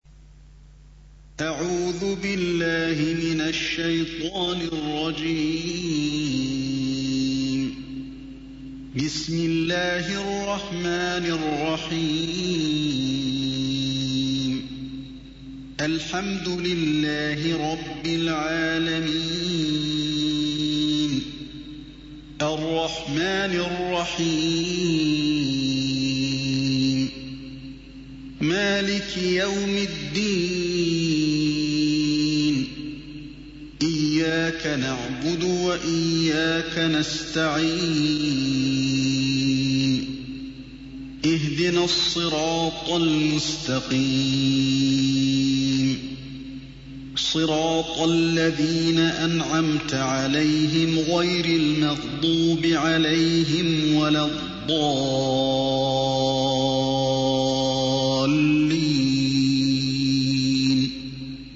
récitation mp3 (lente et claire pour apprentissage) - Hafidh Alî al-Hudayfi (qdlfm) - 245 ko ;
001-Surat_Al_Faatiha_(Prologue)_Cheikh_Ali_Al_Hudayfi.mp3